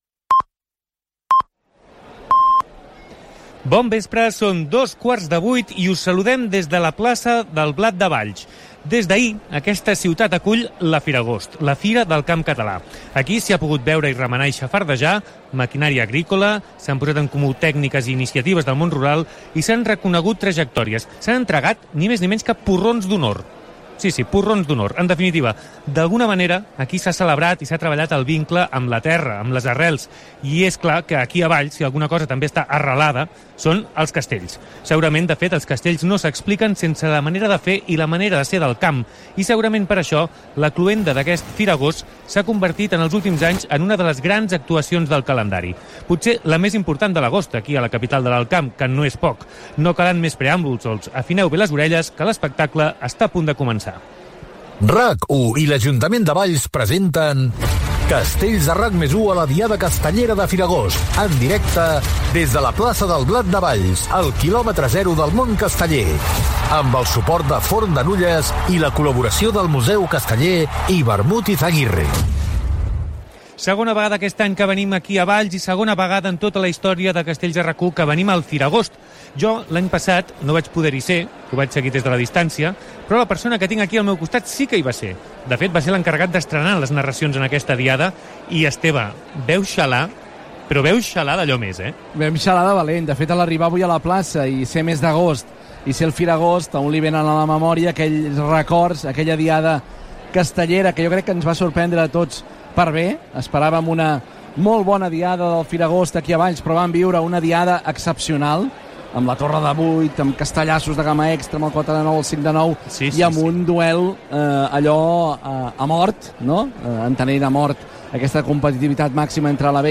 2b717c83b8a3be5b7a042cd03bab8e9cbc343d9a.mp3 Títol RAC+1 Emissora RAC+1 Cadena RAC Titularitat Privada nacional Nom programa Castells a RAC 1 Descripció Transmissió de la diada castellera amb motiu del Firagost de Valls.
Gènere radiofònic Entreteniment